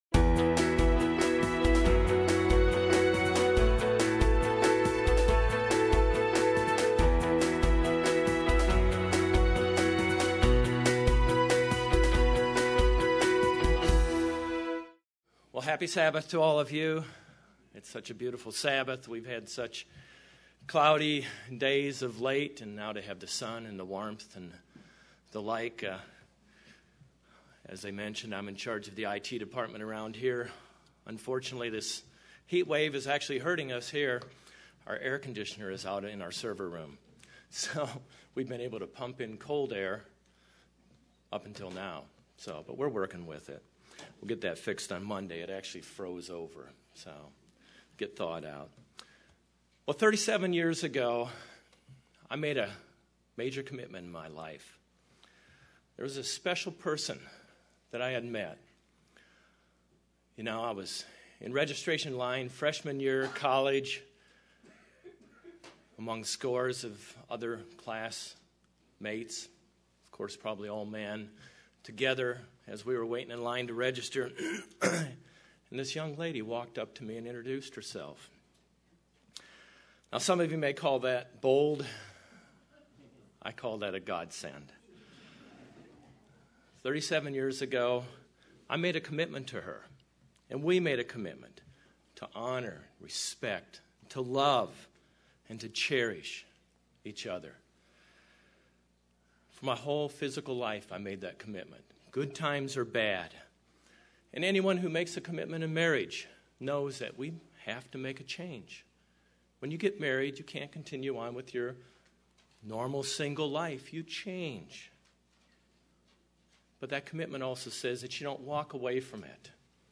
This message looks at the process of baptism and becoming a new person - a new creation.